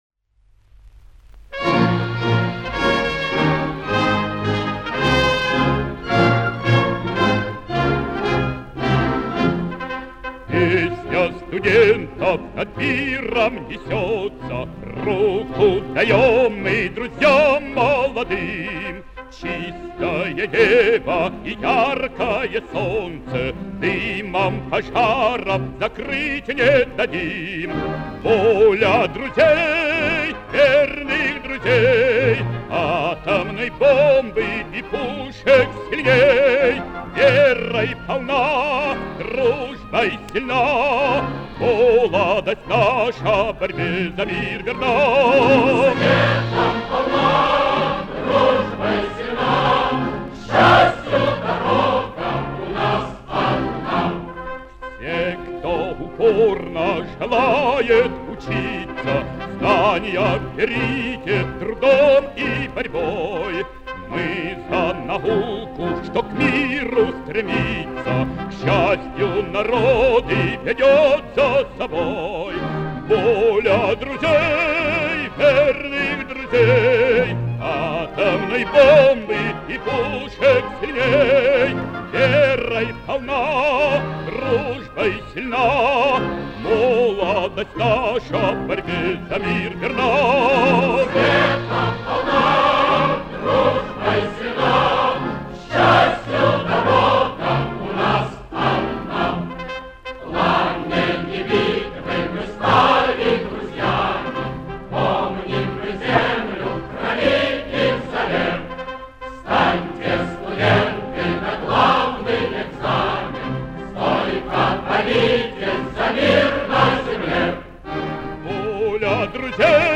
Запись конца 1940-х - начала 1950-х гг.